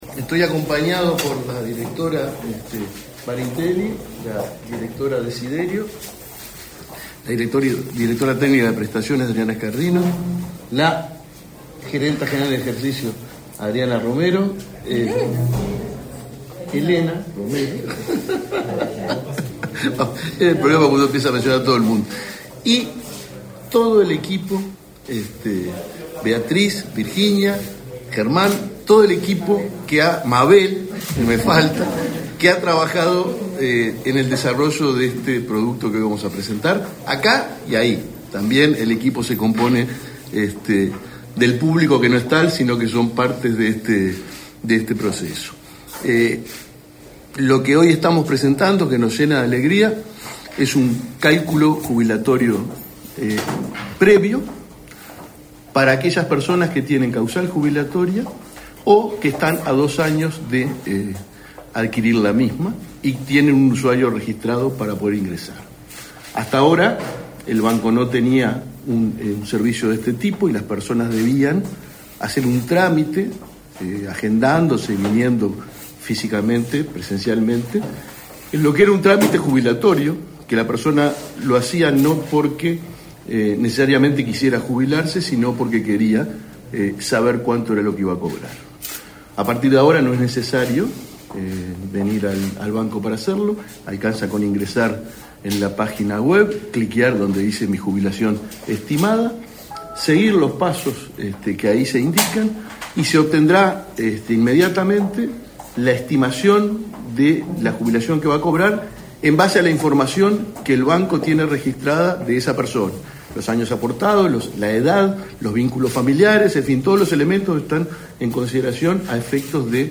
Palabras del presidente del BPS, Alfredo Cabrera
Palabras del presidente del BPS, Alfredo Cabrera 07/06/2024 Compartir Facebook X Copiar enlace WhatsApp LinkedIn El Banco de Previsión Social (BPS) realizó, este 7 de junio, el lanzamiento de un servicio para que quienes estén a poco tiempo de jubilarse puedan estimar los montos que percibirán. Participó en el evento, el presidente del banco, Alfredo Cabrera.